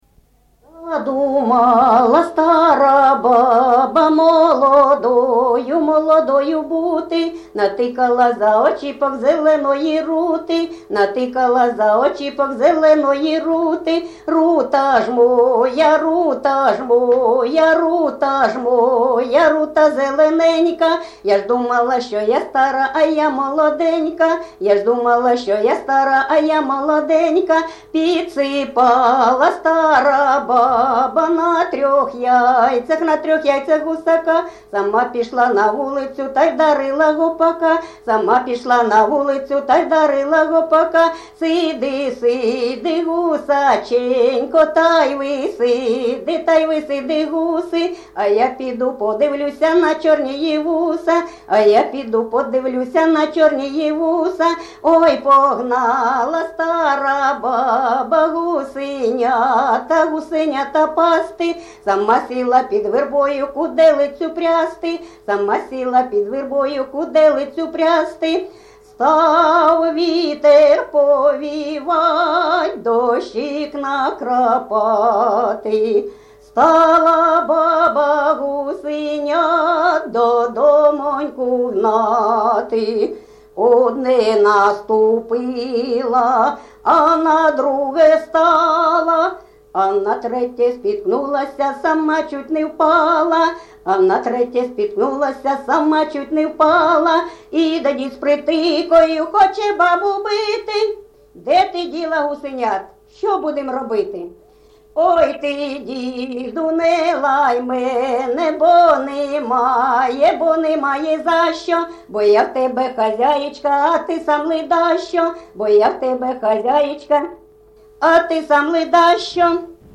ЖанрПісні з особистого та родинного життя, Жартівливі
Місце записум. Часів Яр, Артемівський (Бахмутський) район, Донецька обл., Україна, Слобожанщина